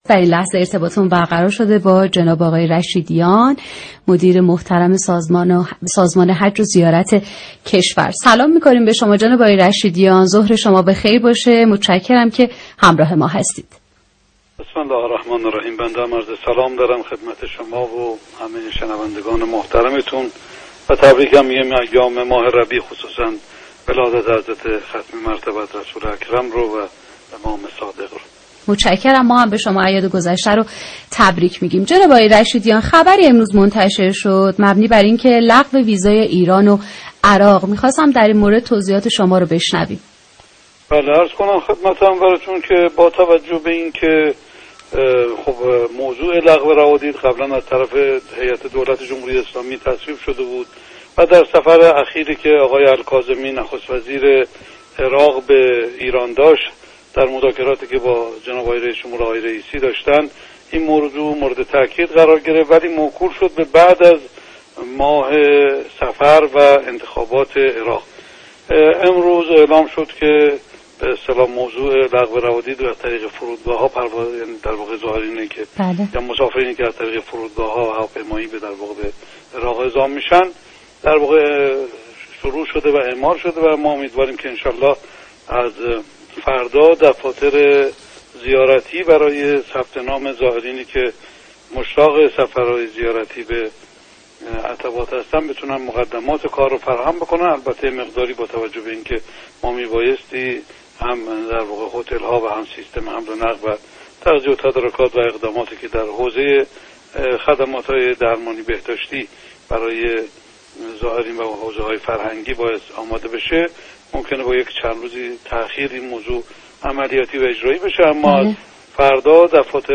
به گزارش رادیو زیارت ، علی رضا رشیدیان در گفتگو با این رادیو و با اعلام لغو روادید برای سفرهای هوایی زائران ایران و عراق توضیح داد: لغو روادید پیش از این تصویب شده بود و در سفر اخیر الکاظمی به ایران رئیس جمهور کشورمان مجدد بر لغو روادید تاکید کرد و بنا شد این اقدام به پس از ماه صفر و انتخابات عراق موکول شود.